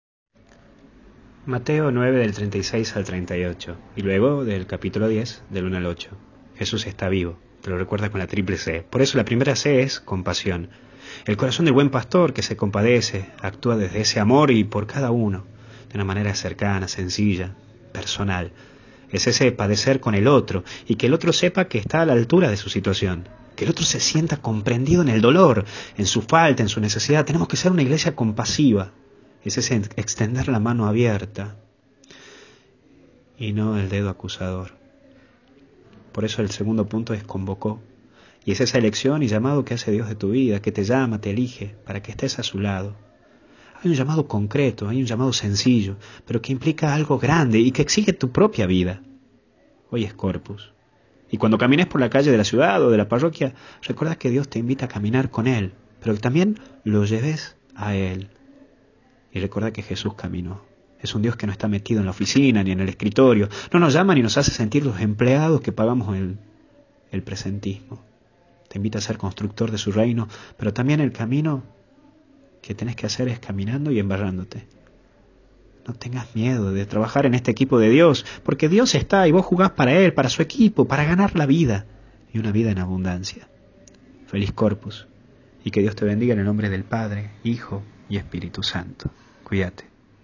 Meditación Diaria